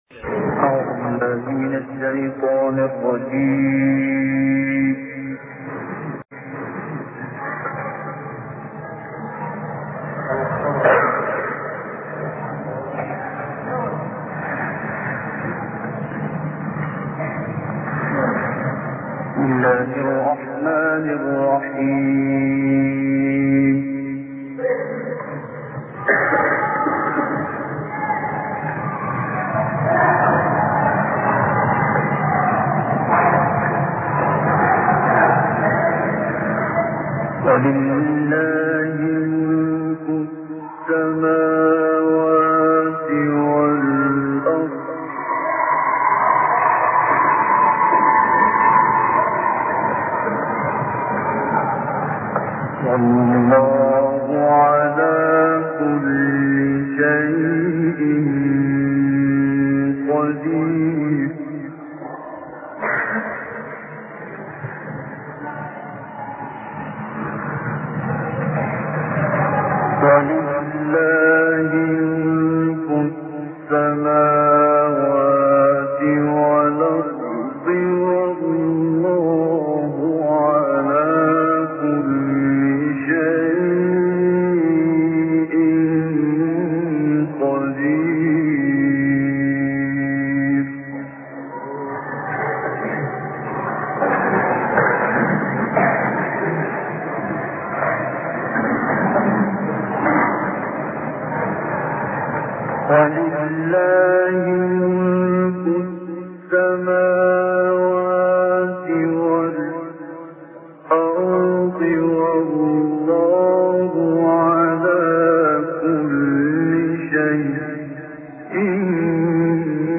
تلاوت آیاتی از سوره آل عمران توسط استاد عبدالباسط محمد عبدالصمد